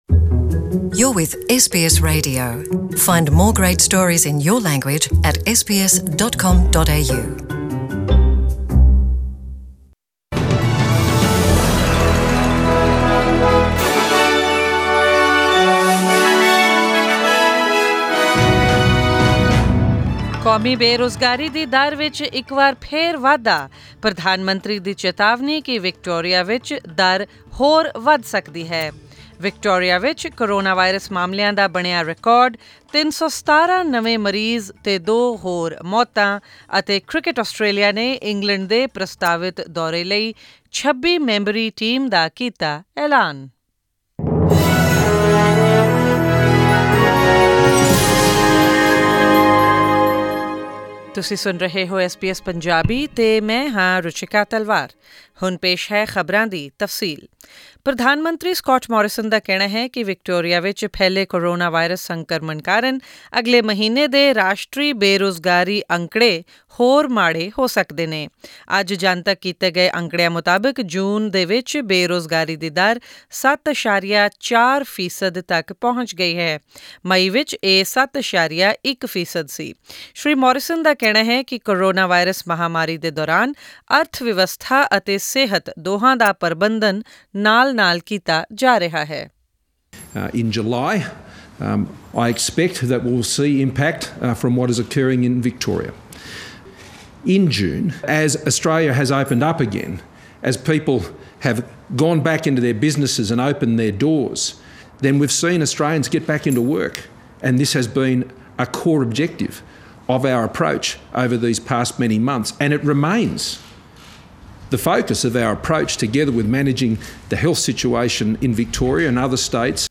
Australian News in Punjabi: 16 July 2020